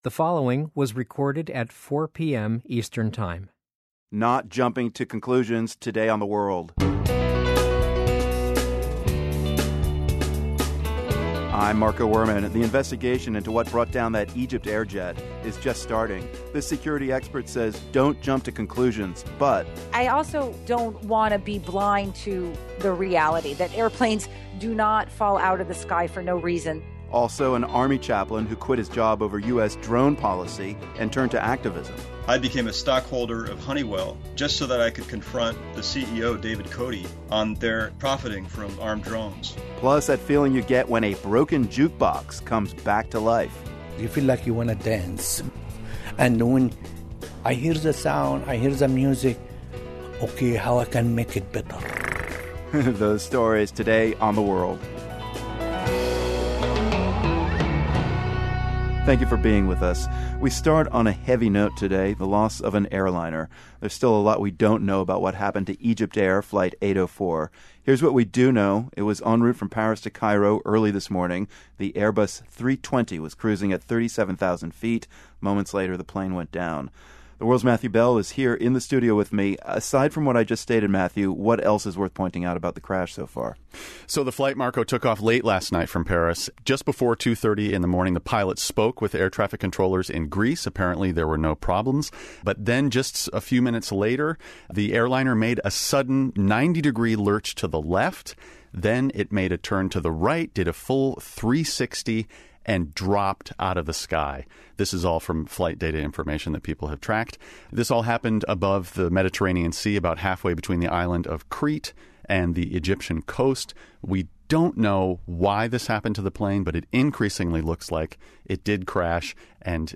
We'll have the latest on EgyptAir Flight 804 that went down in the Mediterranean earlier this morning. We also bring you a conversation with an Army chaplain who quit over the US military drone program. Plus, an engineer who has become the jukebox hero of Los Angeles.